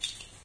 ambience_drippingwater1.ogg